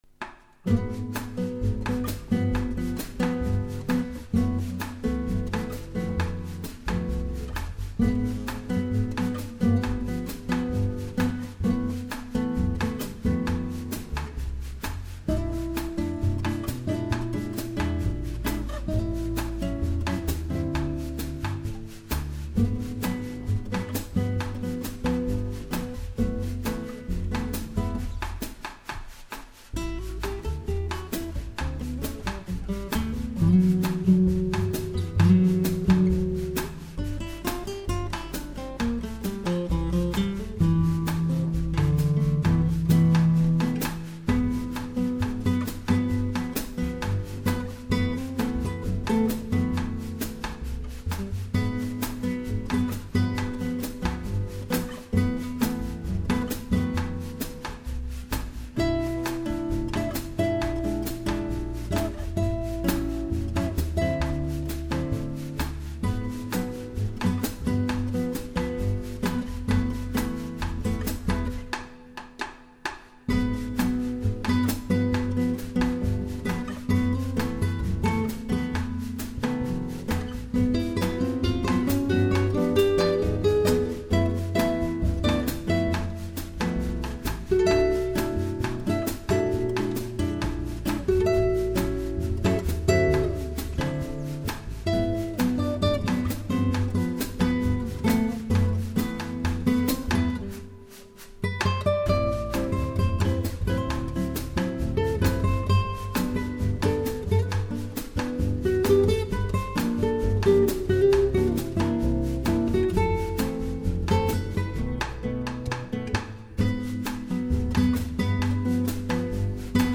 Gypsy